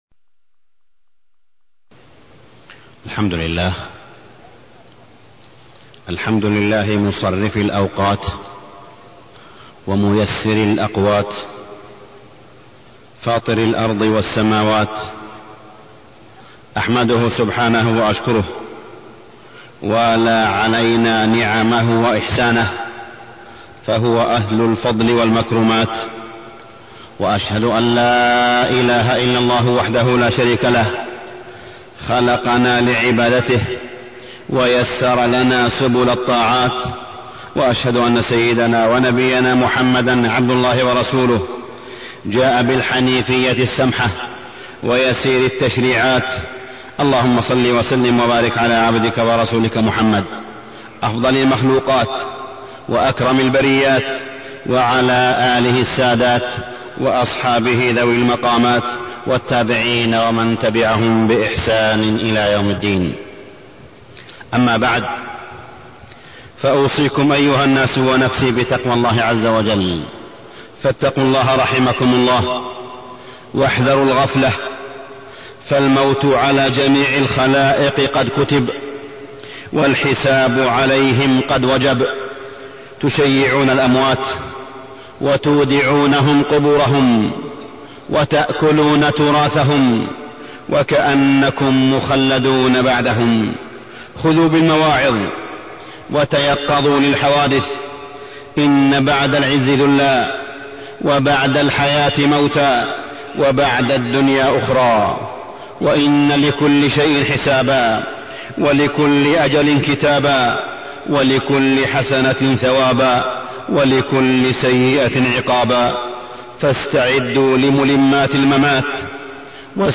تاريخ النشر ٢٠ رجب ١٤٢٣ هـ المكان: المسجد الحرام الشيخ: معالي الشيخ أ.د. صالح بن عبدالله بن حميد معالي الشيخ أ.د. صالح بن عبدالله بن حميد الإستعداد للموت